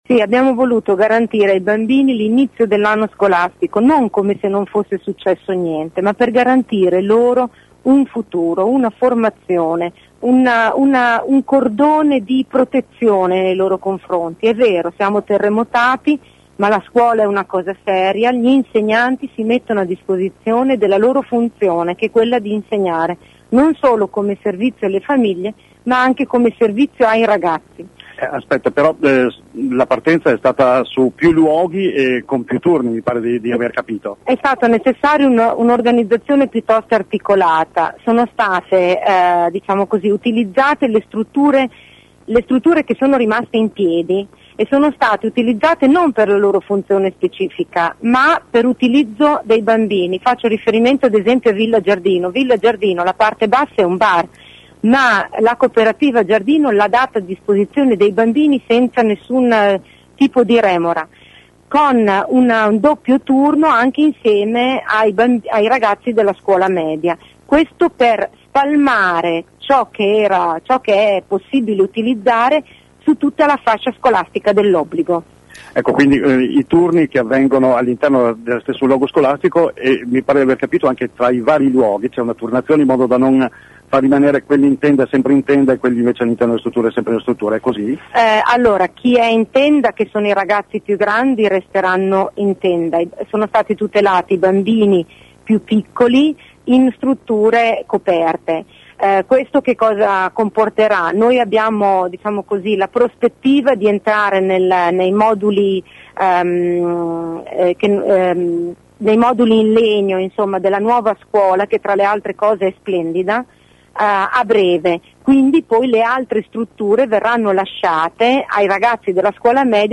Ecco alcune delle voci raccolte questa mattina dai nostri inviati.